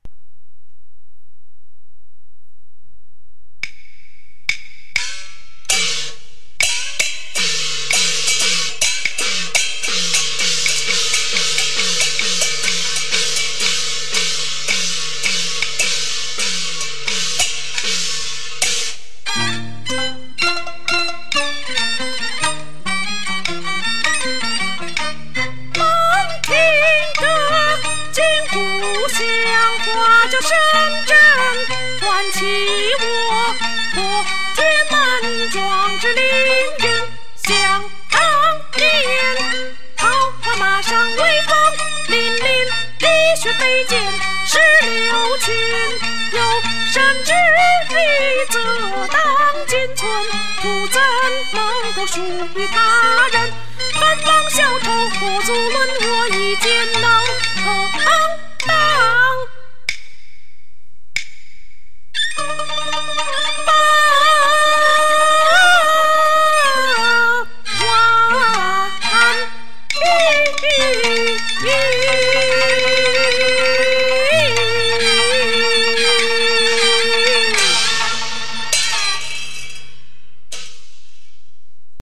【西皮流水】